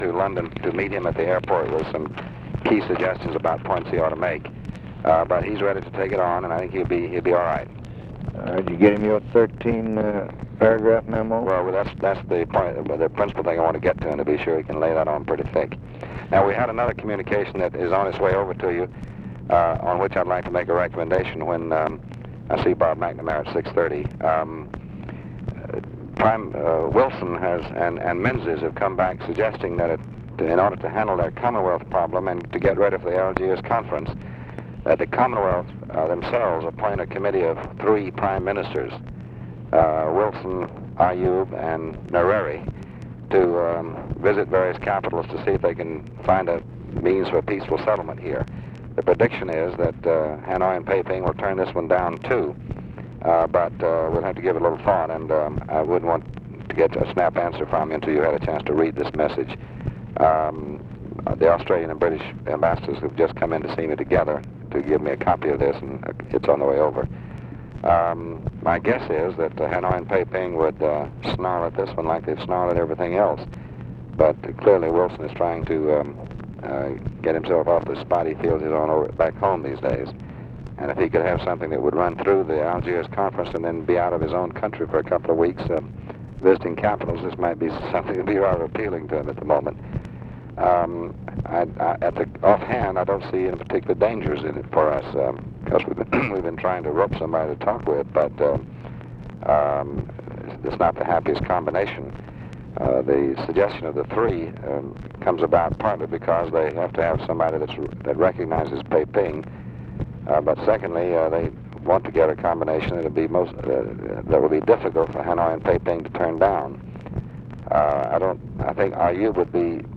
Conversation with DEAN RUSK, June 15, 1965
Secret White House Tapes